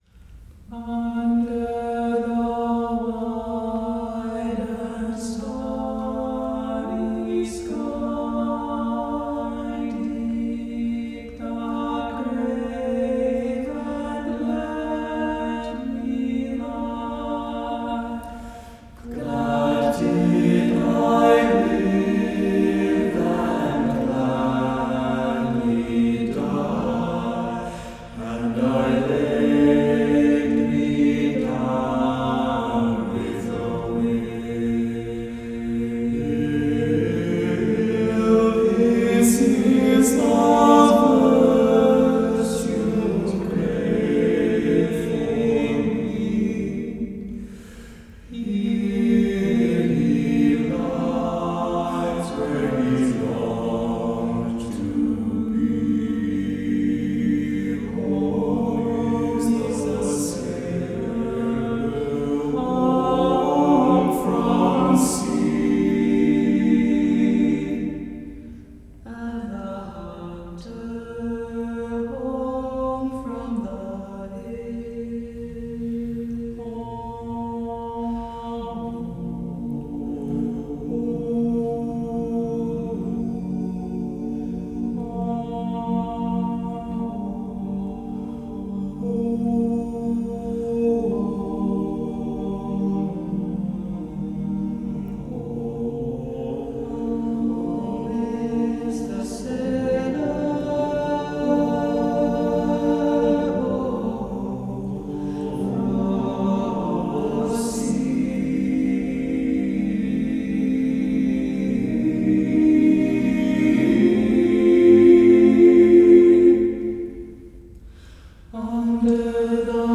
for TTBB a cappella choir
Inspired by Irish folk music